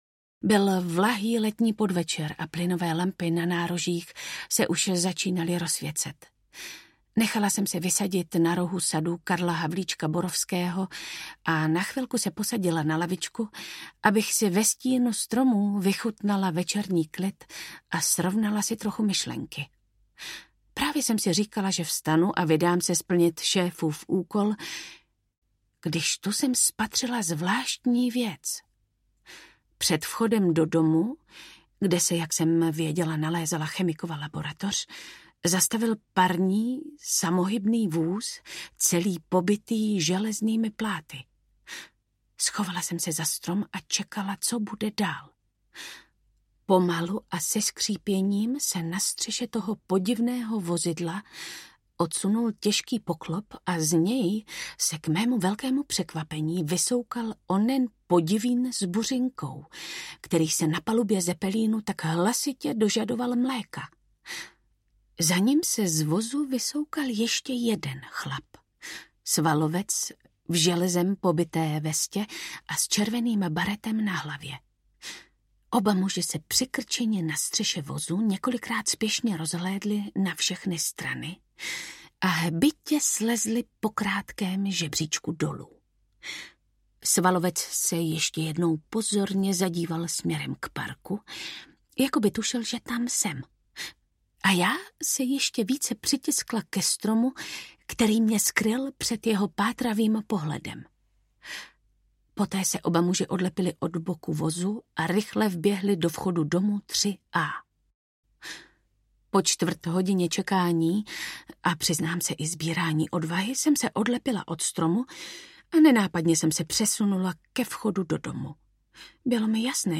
Velká hra audiokniha
Ukázka z knihy
Vyrobilo studio Soundguru.